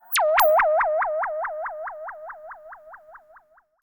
Theremin_FX_06.wav